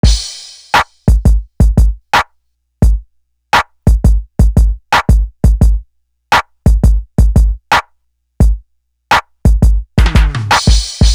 Mpk Drum.wav